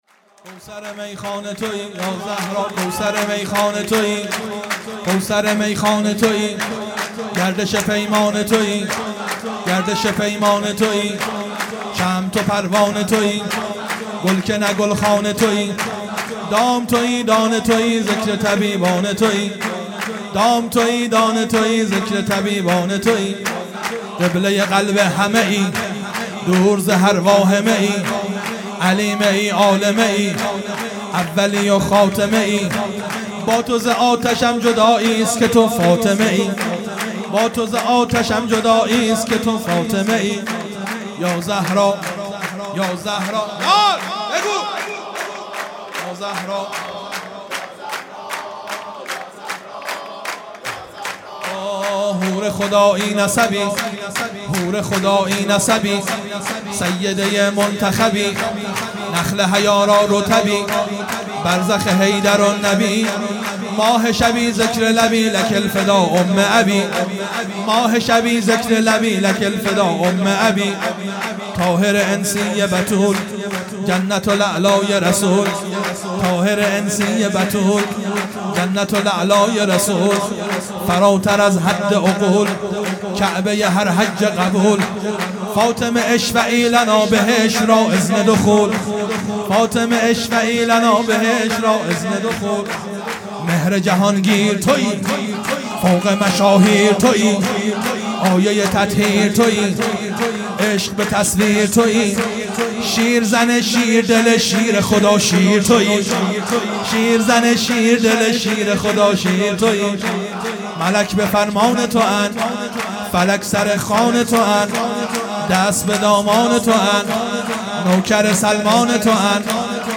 سرود
ولادت حضرت زهرا (س) | ۱۵ اسفند ۱۳۹۶